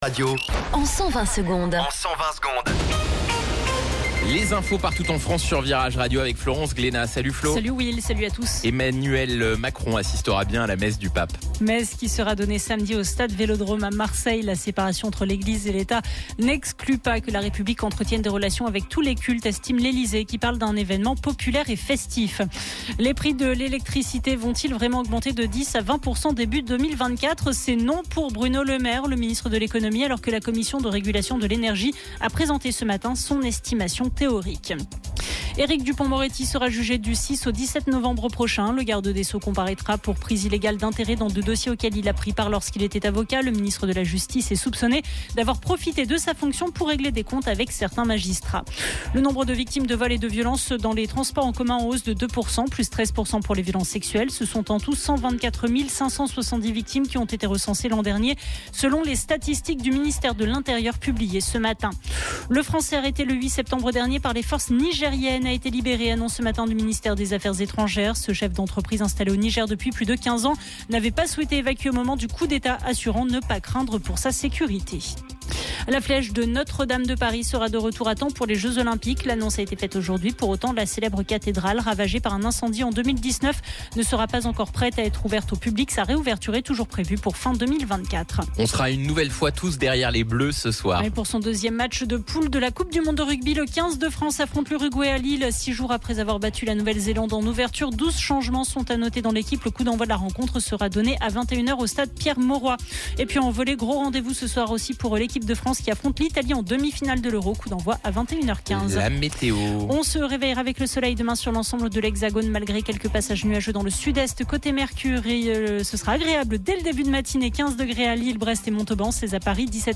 Flash Info National